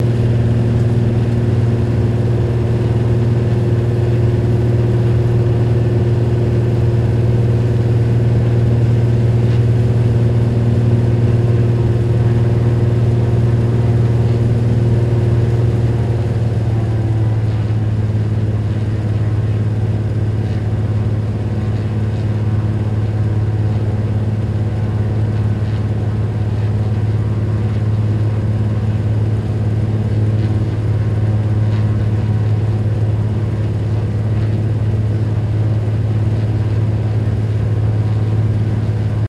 Cessna Prop Plane Interior Constants